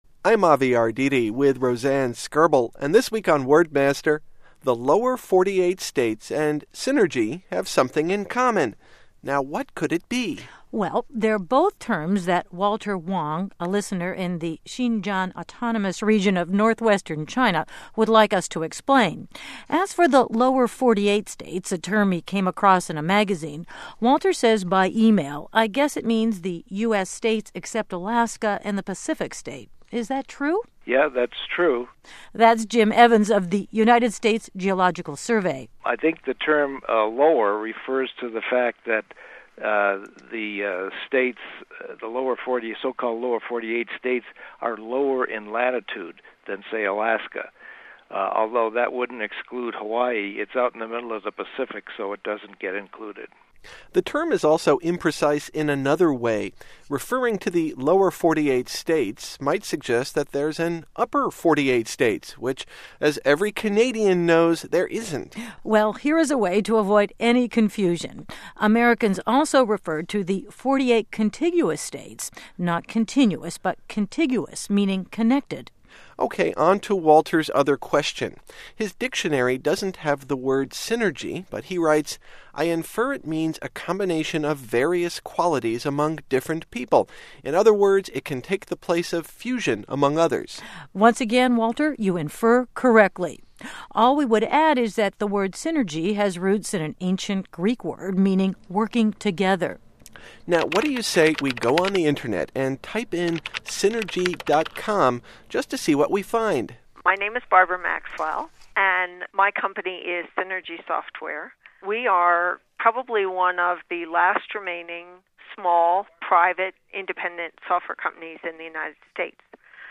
Some American roots music by the group Holy Modal Rounders, going back to 1972.